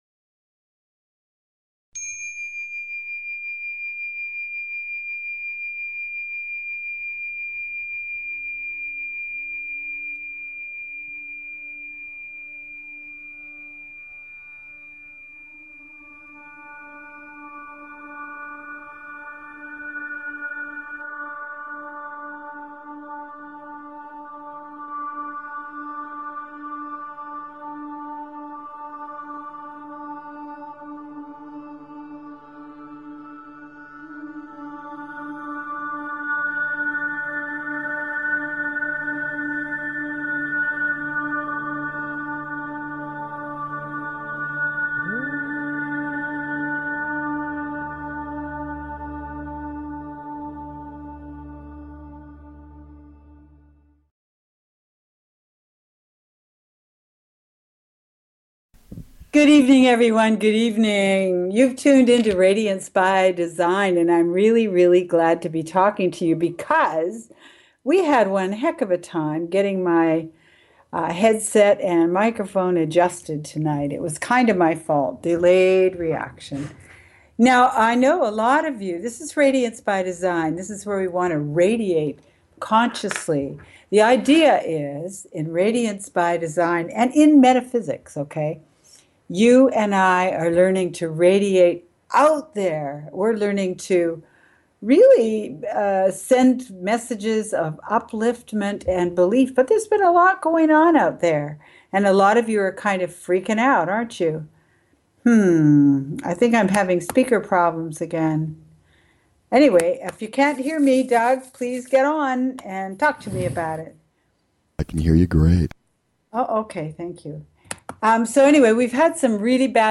Live Spiritual Readings